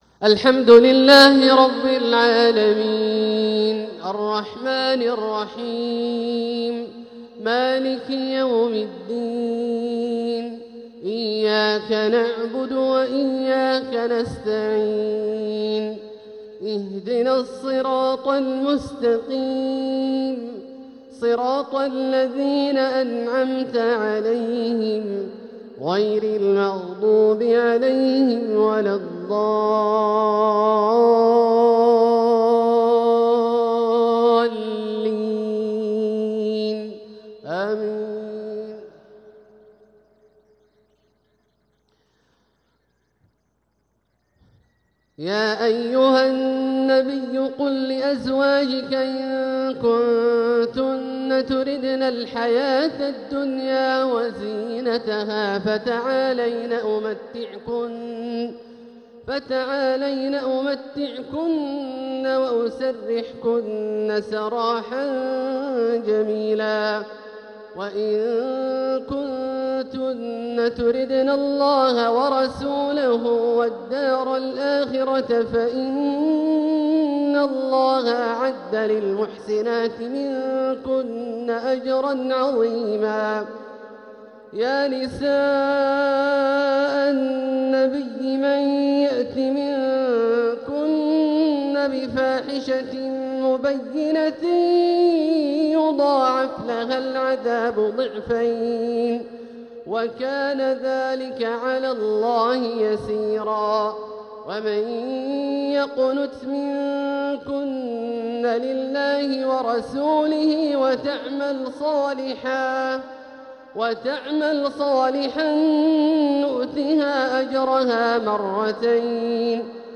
تلاوة من سورة الأحزاب | عشاء الجمعة 14 صفر 1447هـ > ١٤٤٧هـ > الفروض - تلاوات عبدالله الجهني